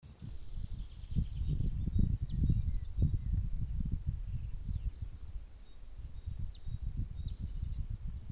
Птицы -> Синицевые ->
гаичка, Poecile palustris
СтатусПоёт